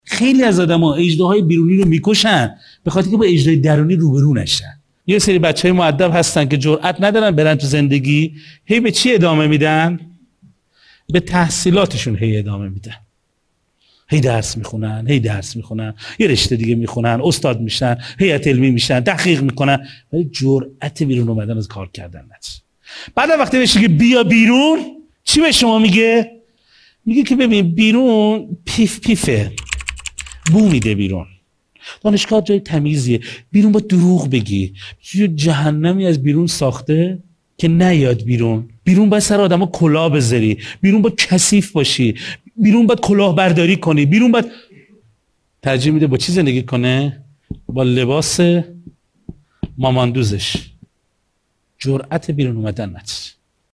کار باید بدون چالش باشد...کسی به آدم گیر ندهد...نقد و بحثی نباشد...راحت راحت راحت شما تا به حال به کسی که تصورات اینگونه داشته باشد، برخورد کرده اید؟منبع: کلاس صوتی اعتماد به نفس.